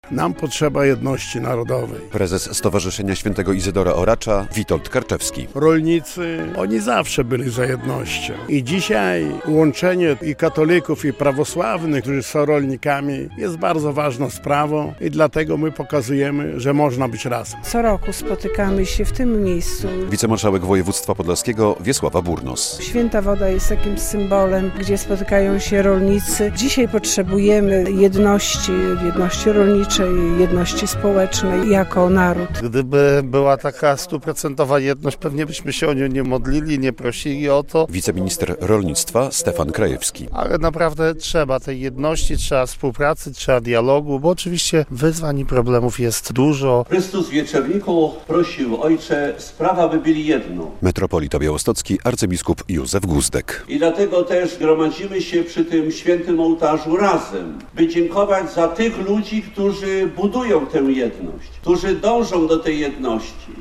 Obchody Dnia Jedności Rolniczej w Sanktuarium w Świętej Wodzie
W podobnym tonie wypowiada się wiceminister rolnictwa Stefan Krajewski.